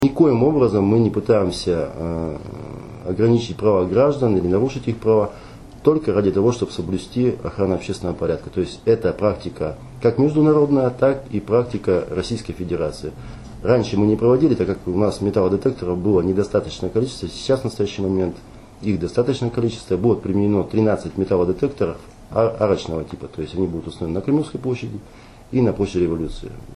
Как рассказал корреспонденту ИА «СеверИнформ» заместитель начальника полиции Управления МВД России по Вологодской области Сергей Иванов, такие меры безопасности в Вологде будут применяться впервые.